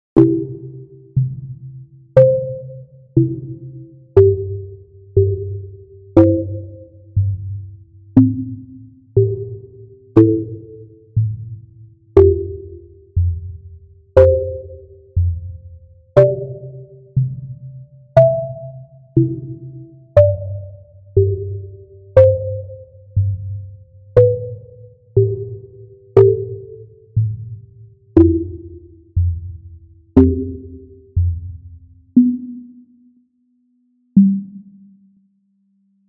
幻想的でゆっくりな曲です。
BPM100